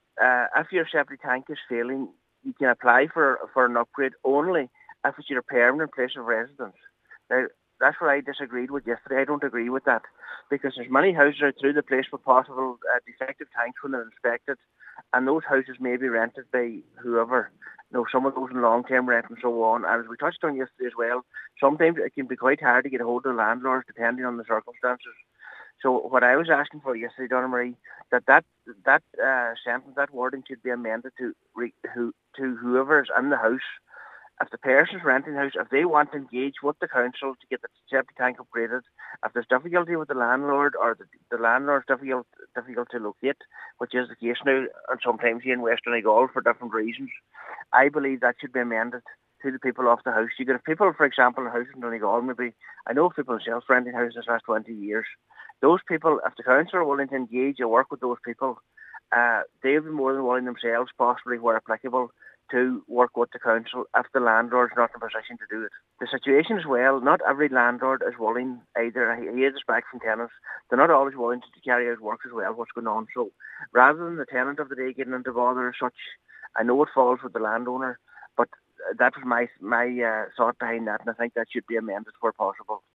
Cllr. Clafferty says this excludes rented properties and is causing issues: